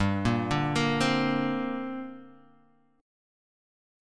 Gmadd11 chord {3 1 0 3 1 x} chord